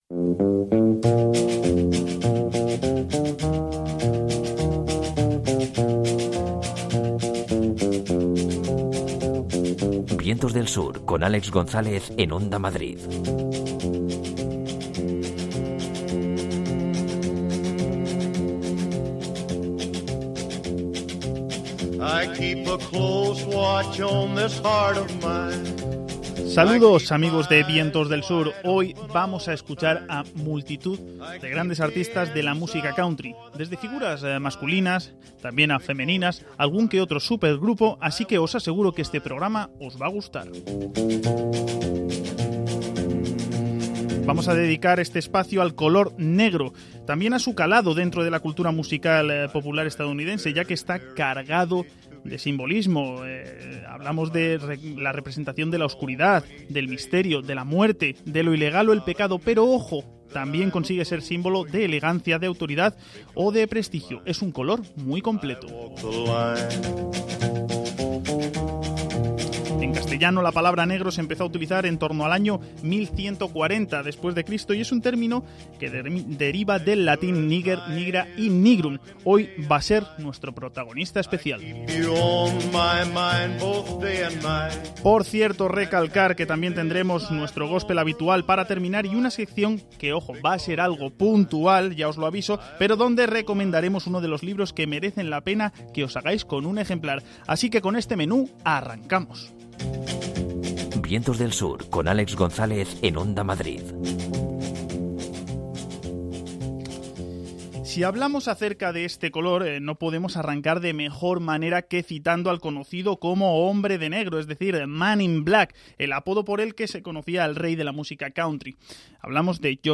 El final dedicado al Gospel viene representado por “I saw the light”.